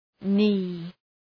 Προφορά
{ni:}